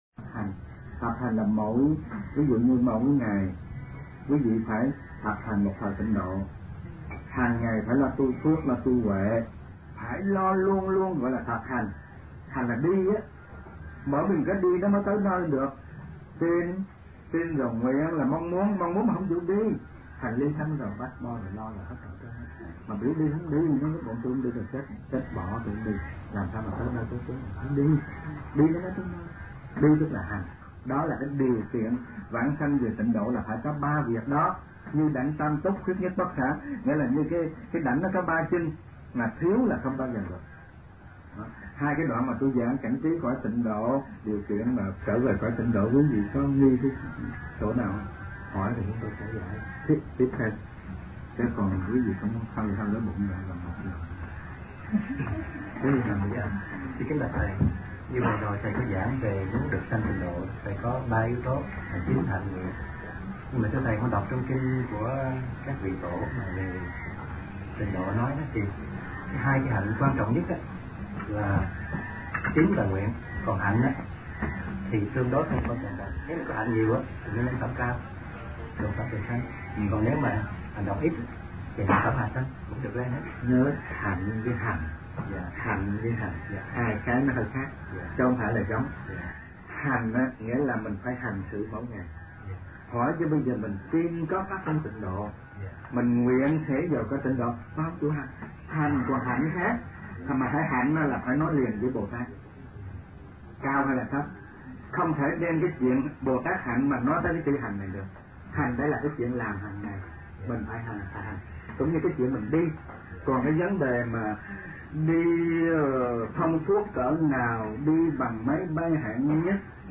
Kinh Giảng Pháp Môn Tịnh Độ